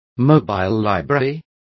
Also find out how bibliobus is pronounced correctly.